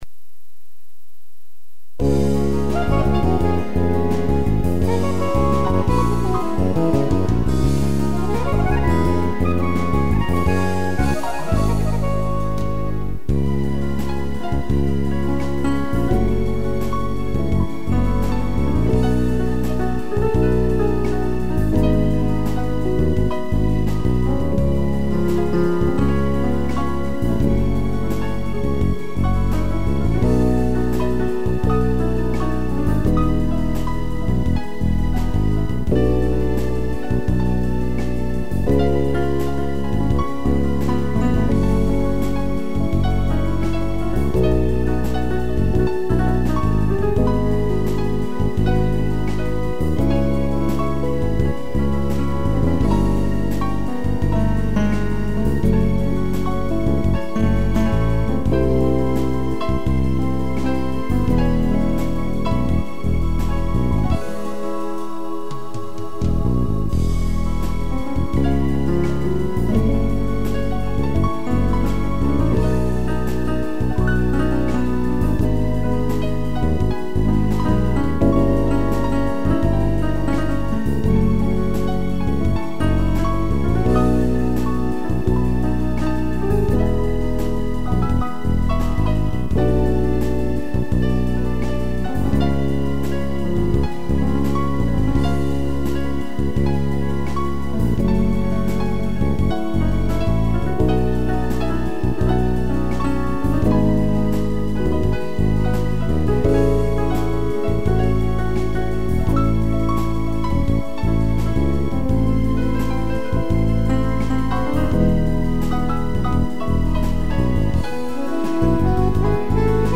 piano
instrumental